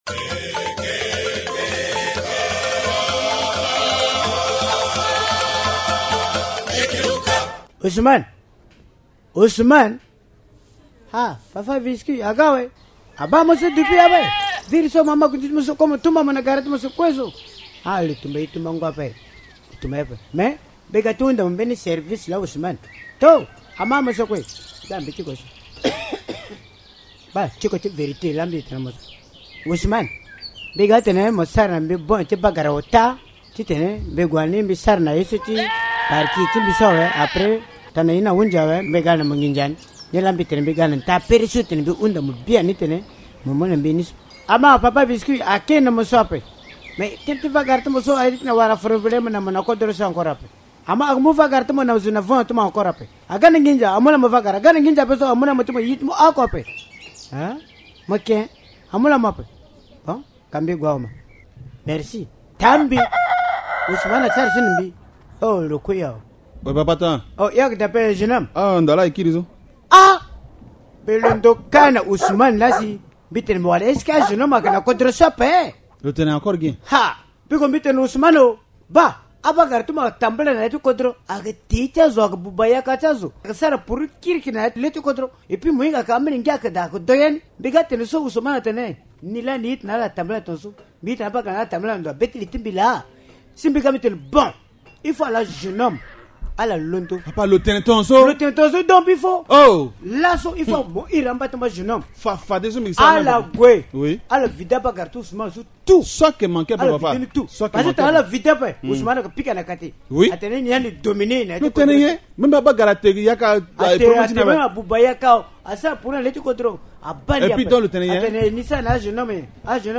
Aujourd’hui, la jeunesse est utilisée à d’autres fins et détournée de la bonne voie. Les comédiens du village Linga l’ont illustré.